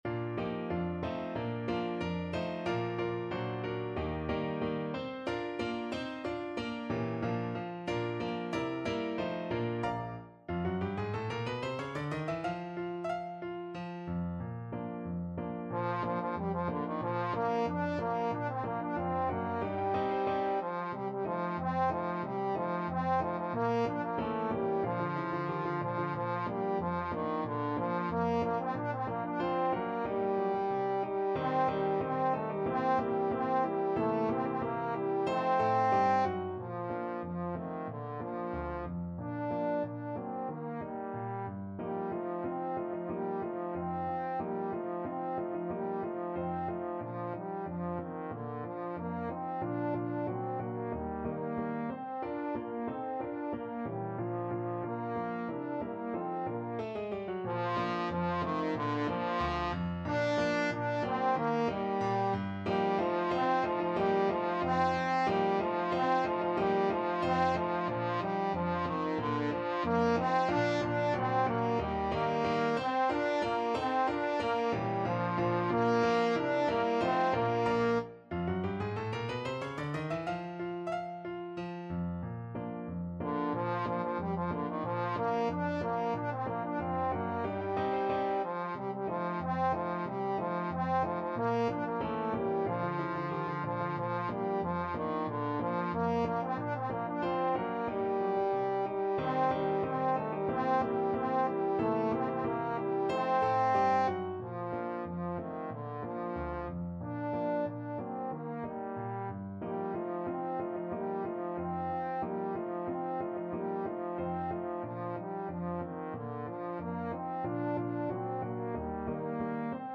Allegretto =92
2/4 (View more 2/4 Music)
D4-D5
Traditional (View more Traditional Trombone Music)